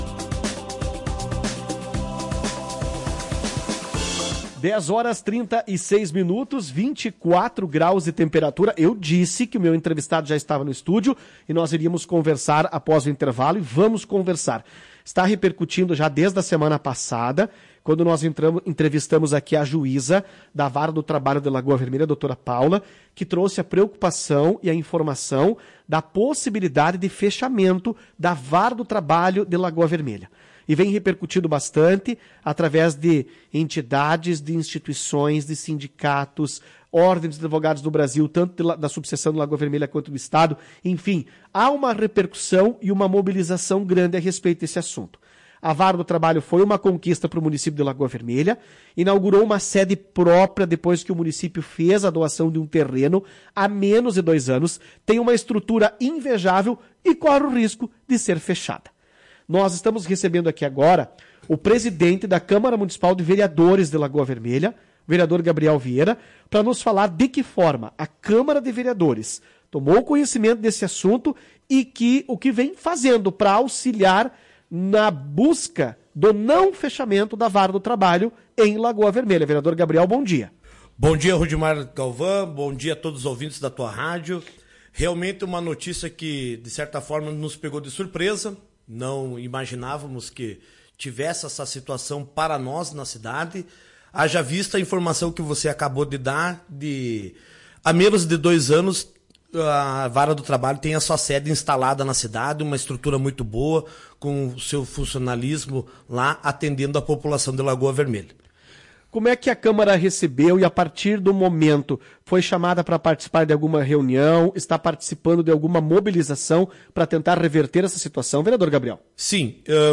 Gabriel Vieira - Presidente da Câmara de Vereadores de Lagoa Vermelha